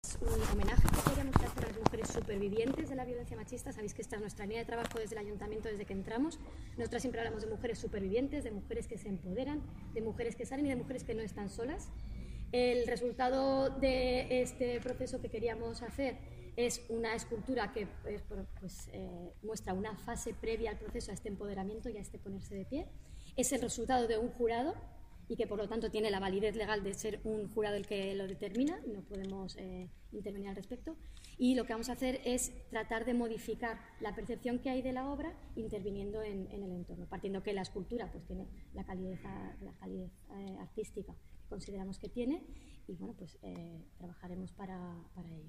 Declaraciones de la concejala de Educación e Inclusión, Arantza Gracia, sobre el Memorial contra la violencia machista